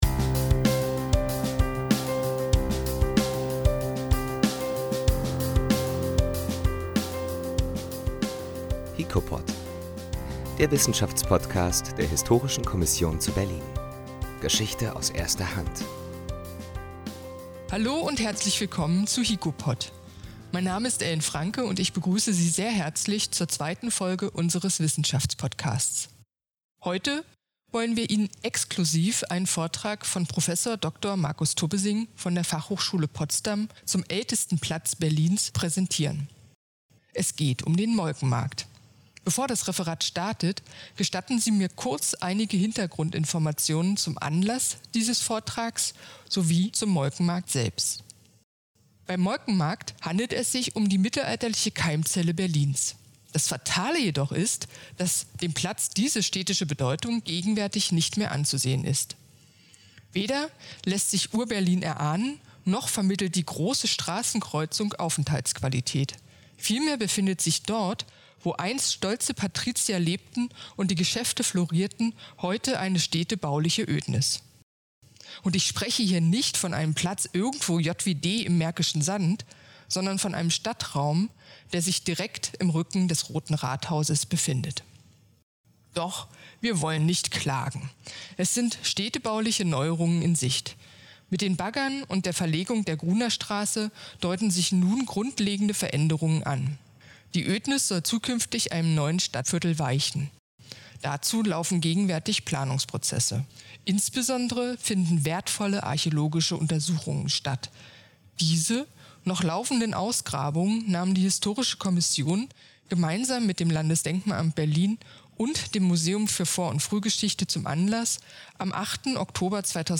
Aus diesem Anlass führte die Historische Kommission zu Berlin gemeinsam mit Kooperationspartnern ein wissenschaftliches Kolloquium zum Molkenmarkt durch